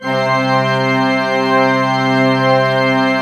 Index of /90_sSampleCDs/Propeller Island - Cathedral Organ/Partition I/PED.V.WERK R